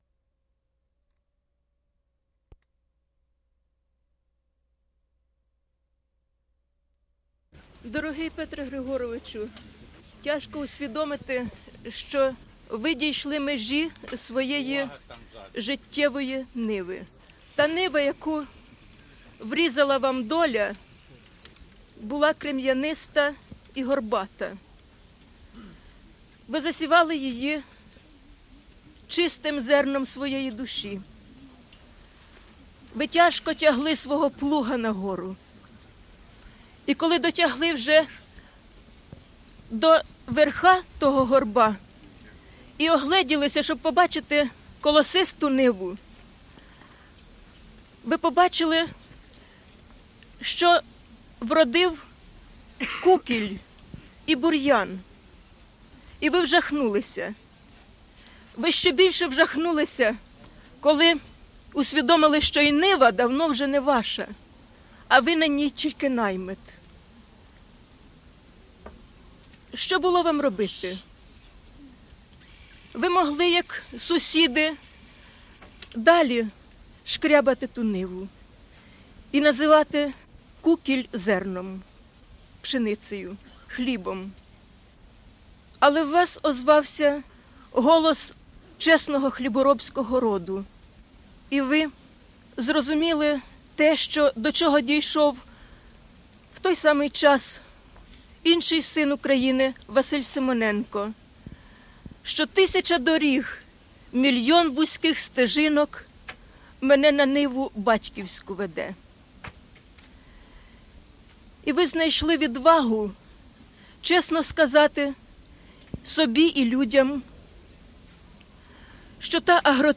Statement by Lev Kopolev regarding Vasyl' Stus
Reading of poem by Vasyl Stus